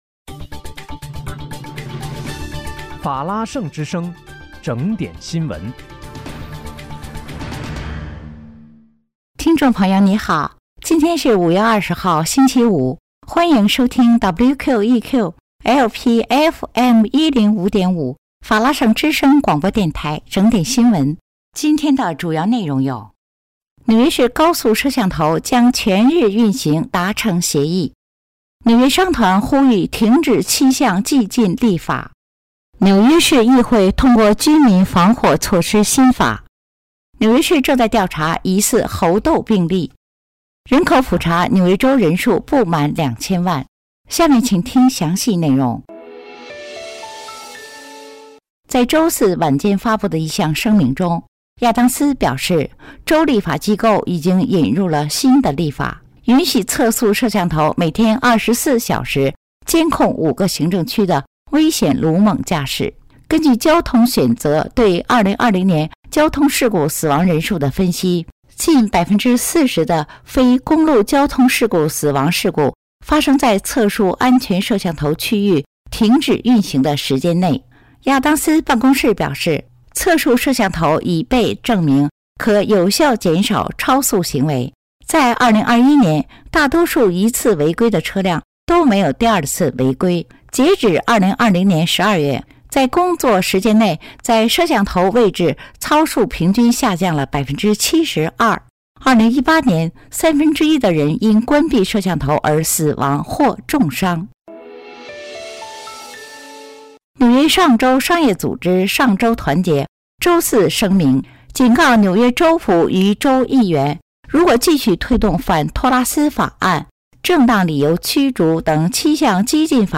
5月20日（星期五）纽约整点新闻